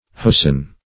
Hosen \Ho"sen\, n. pl.